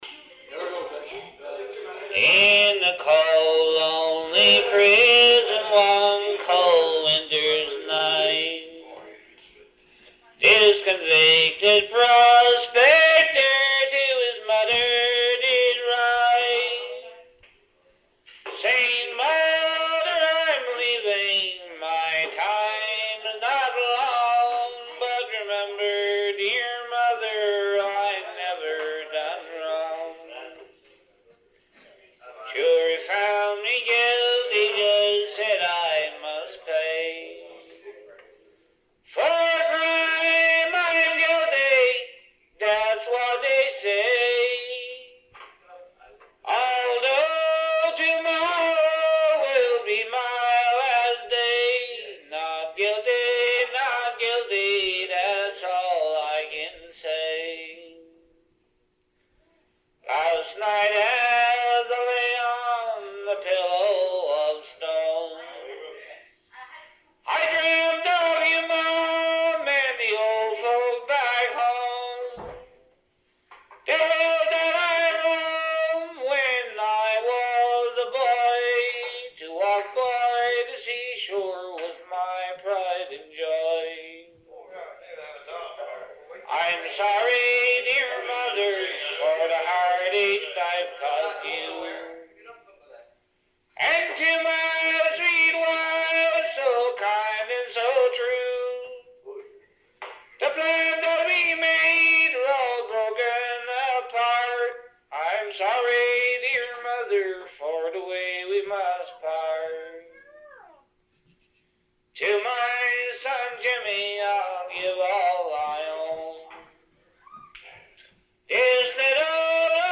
in the powerful old-time “ballad” style—that is, with no instrumental backup. This was the way many singers once performed in rural communities, their voices powerful enough to carry the song and story amid a roomful of people.